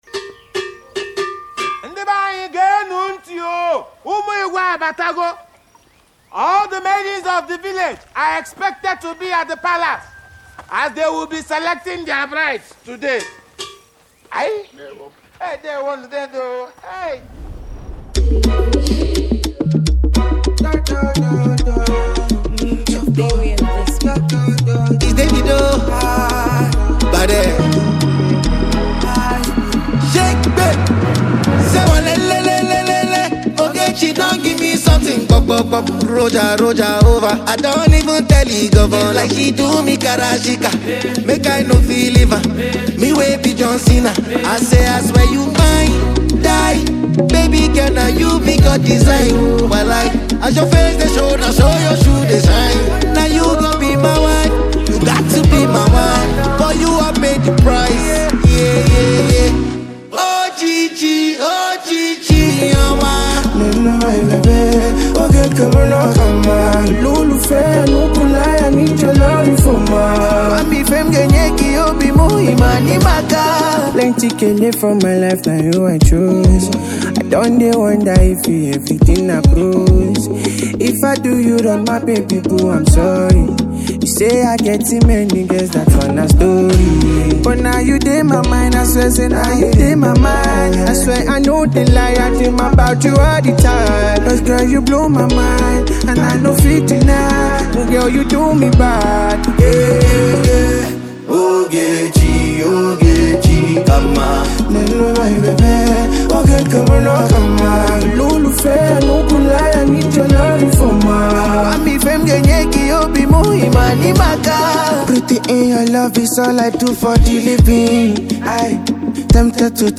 is a collaborative remix